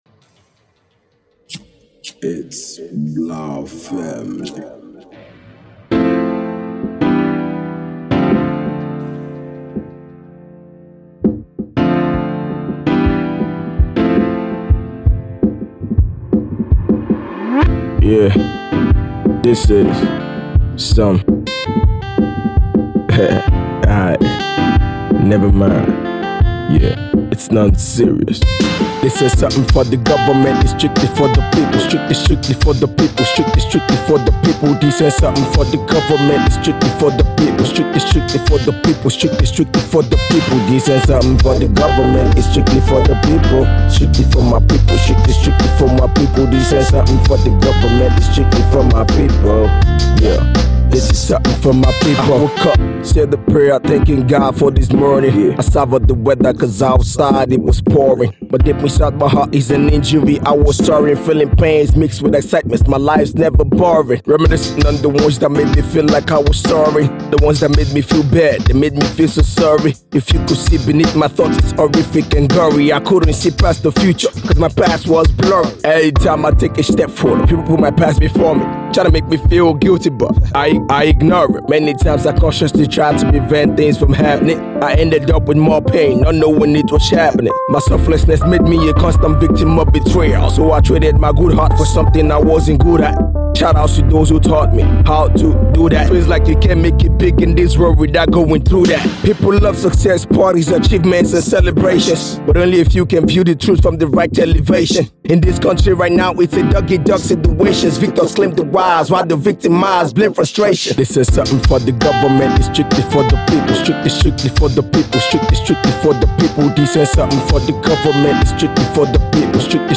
Conscious, Hip-Hop
Haunting Production